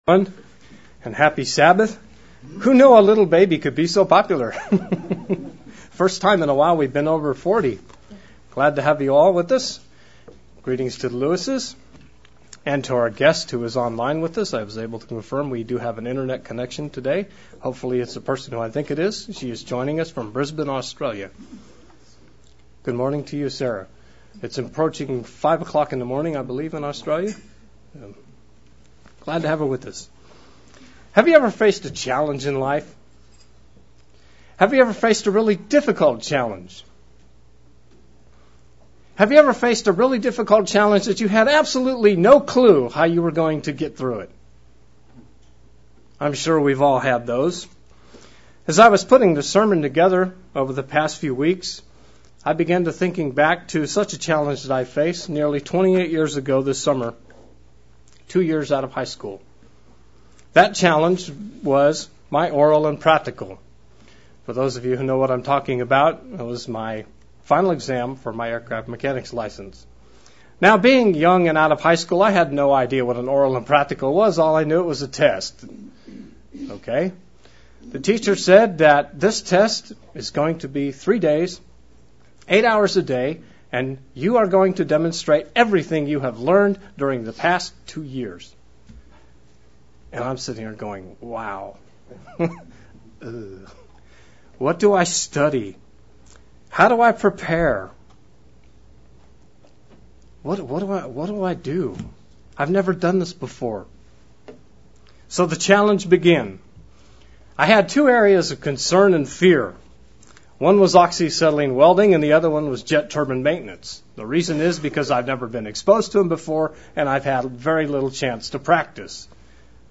Given in Central Illinois
UCG Sermon Studying the bible?